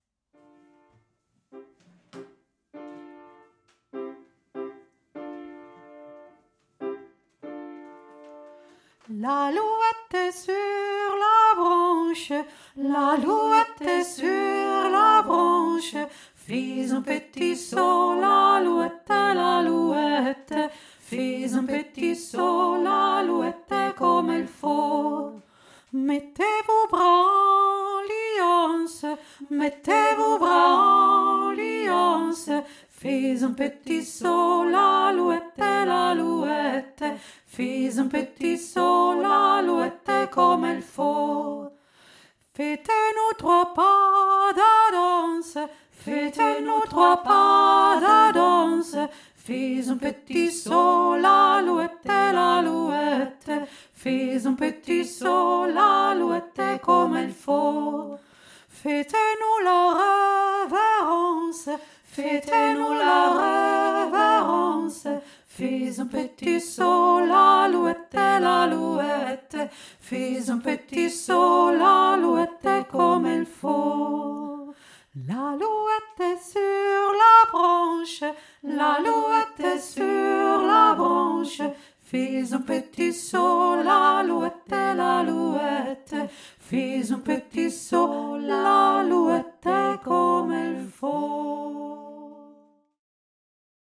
Französisches Tanz-Lied
L´aluette sur la branche (dreistimmig)
l-aluette-dreistimmig.mp3